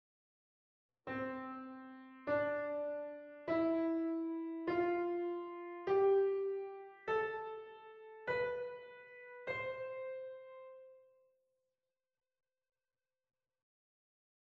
Ionian mode
The intervals from this mode can be taken from the C major scale.
ionian-mode.mp3